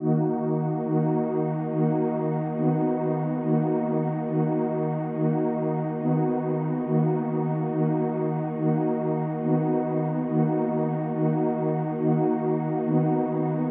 描述：方舟陷阱垫，请联系我合作或免费定制循环。
声道立体声